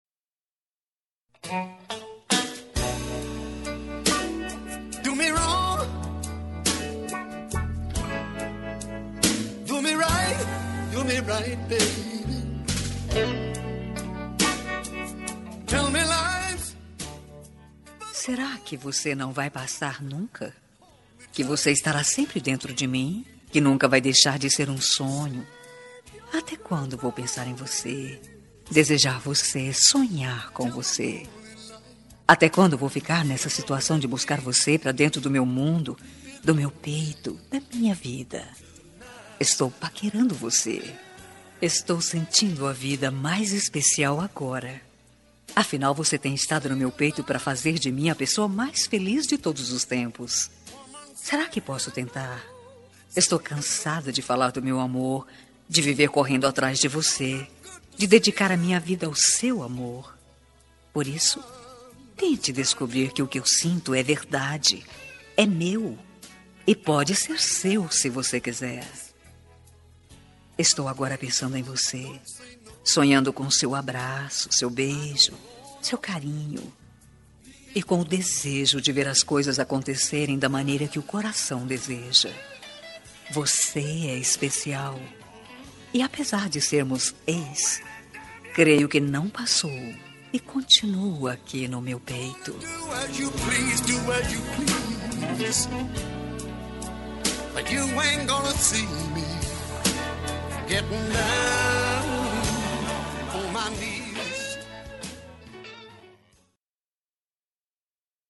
Telemensagem de Paquera – Voz Feminina – Cód: 2139